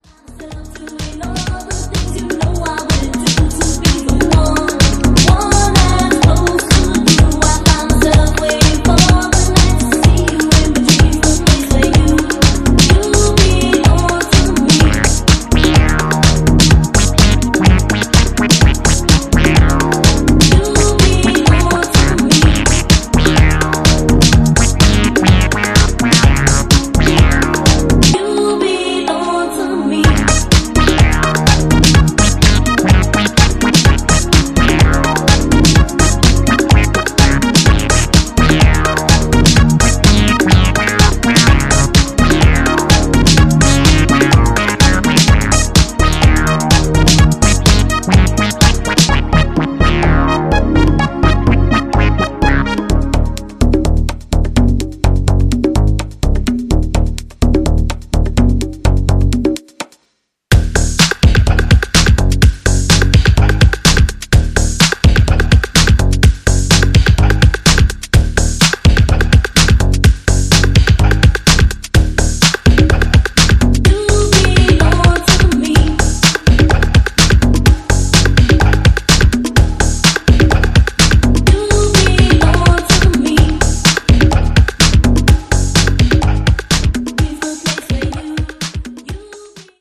本作では、クラシカルなエレクトロや初期UKハウス、アシッド/プロト・ハウス等をオマージュした楽曲を展開。